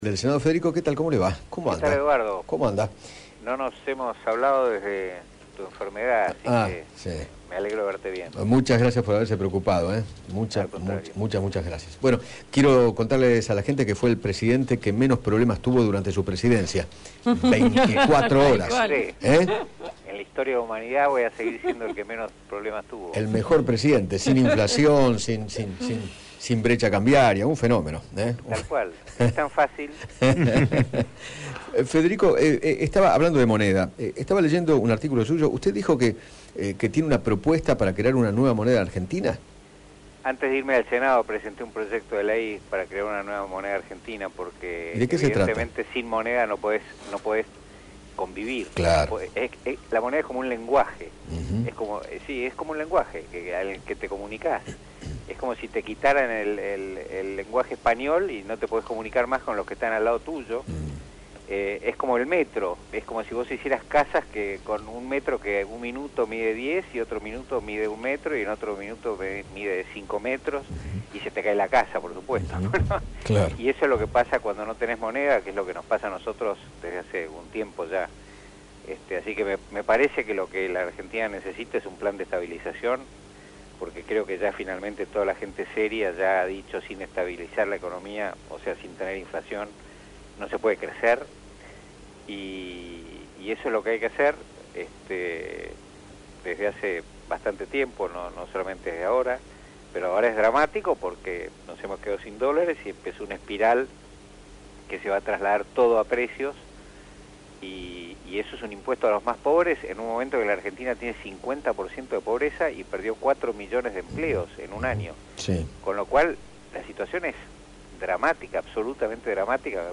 Federico Pinedo, ex Presidente del Senado, dialogó con Eduardo Feinmann sobre los planes del gobierno para paliar la crisis económica y opinó que “si no se achica el gasto público, ¿quién lo va a pagar? La respuesta más terrible y atroz es que lo harán los pobres con la inflación”.